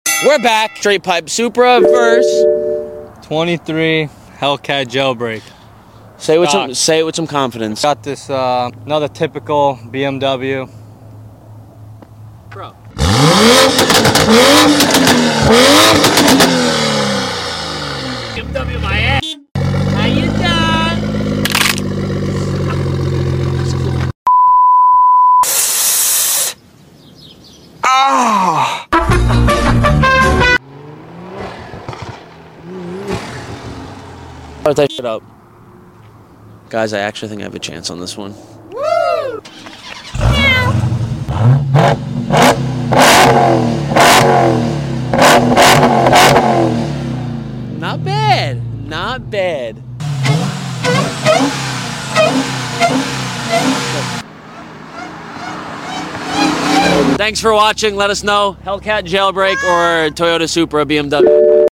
Hellcat Jailbreak vs Toyota Supra sound effects free download
Hellcat Jailbreak vs Toyota Supra REV BATTLE